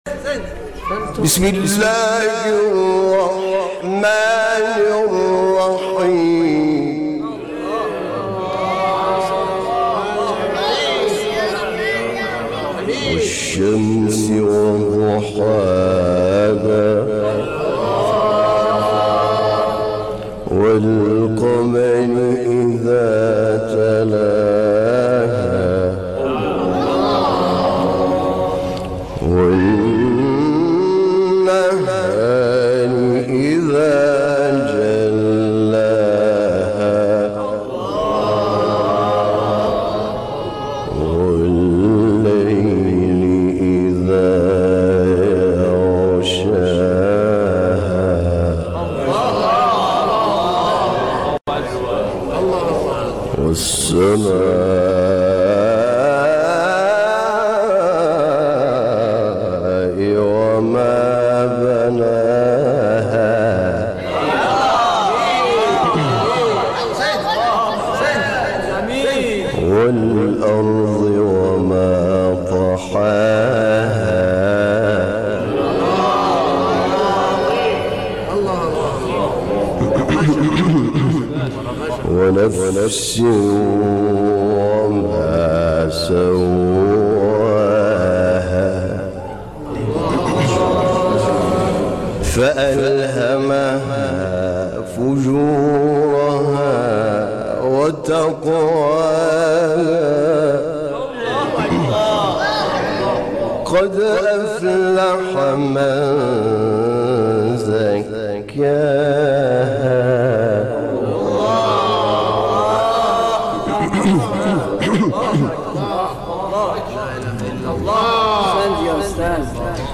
تلاوت سوره شمس با صدای حامد شاکرنژاد